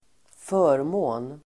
Uttal: [²f'ö:rmå:n]